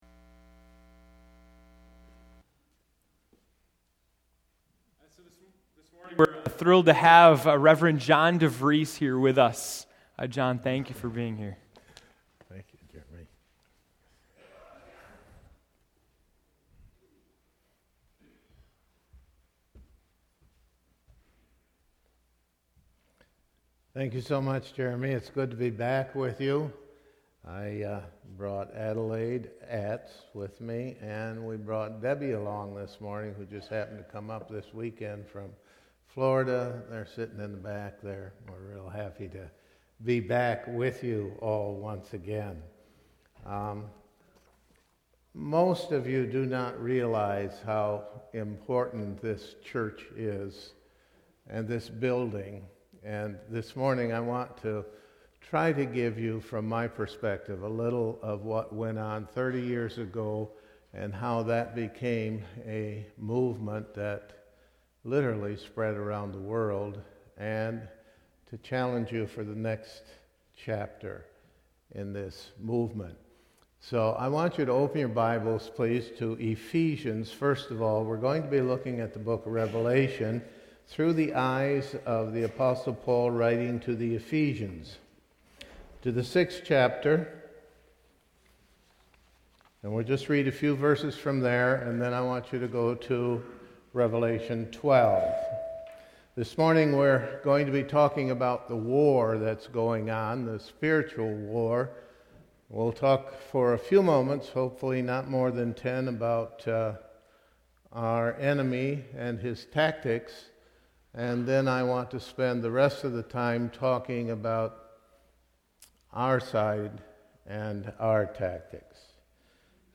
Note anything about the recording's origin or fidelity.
November 24, 2013 (Morning Worship)